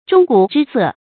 钟鼓之色 zhōng gǔ zhī sè 成语解释 见“钟鼓之色”。